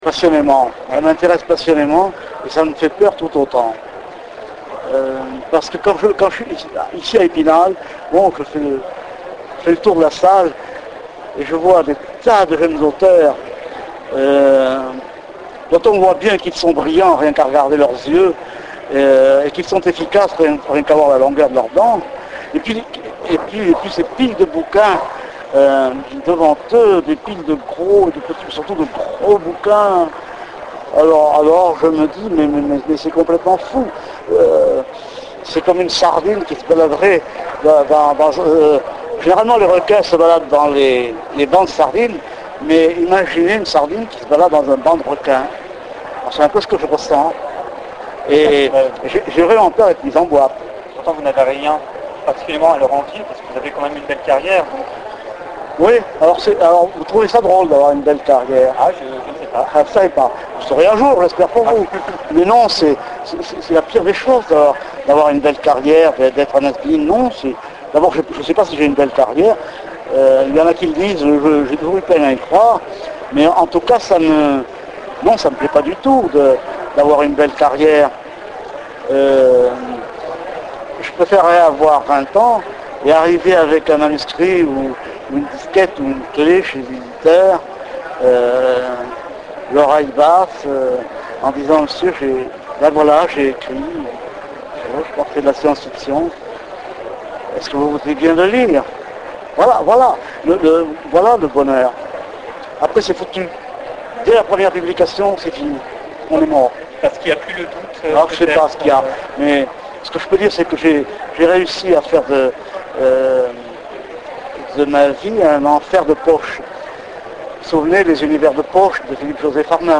Interview Michel Jeury - Mai 2007
La réponse de Michel Jeury